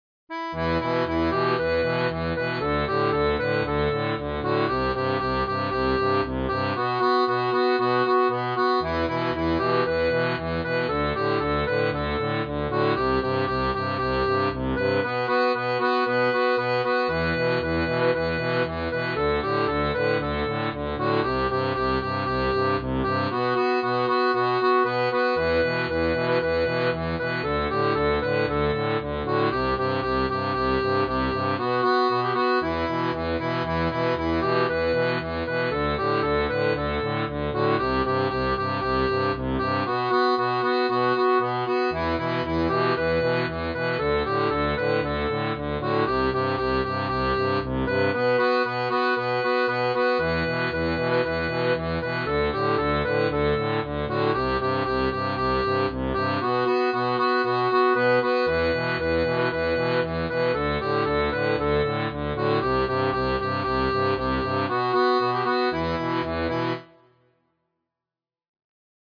• Une tablature pour diato 3 rangs à la tonalité originale
Chant de marins